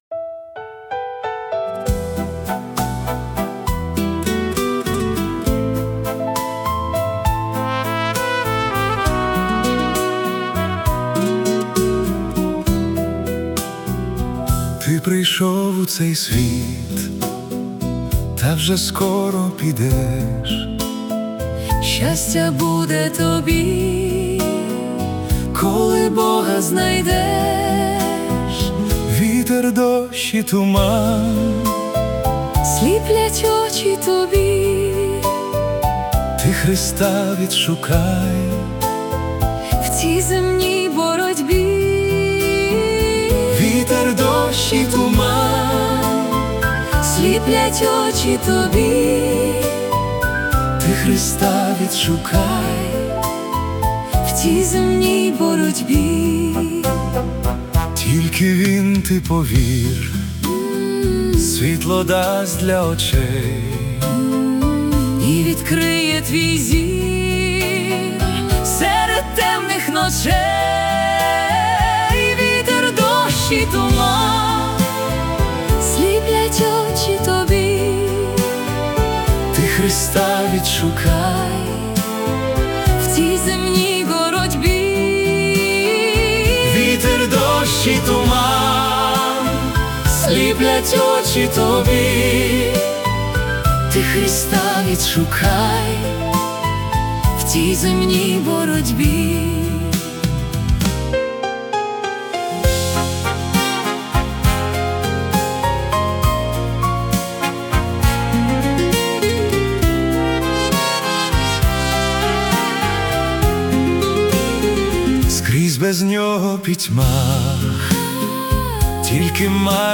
Духовний ретро-вальс про пошук істини.
Класичний вальс з глибоким духовним змістом 🎶